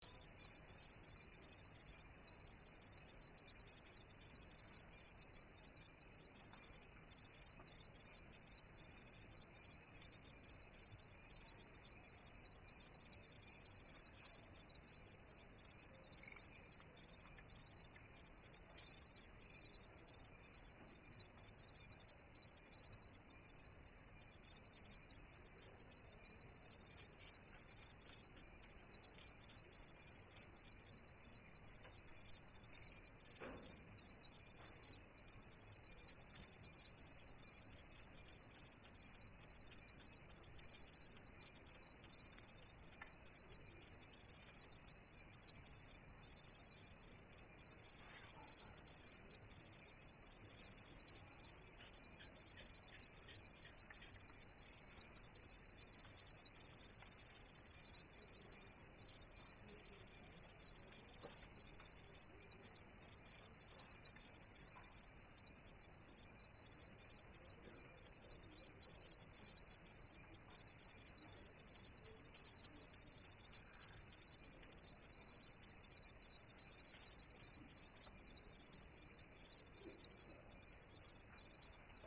Night Cricket Ambience Téléchargement d'Effet Sonore
Animal Sounds Soundboard1,512 views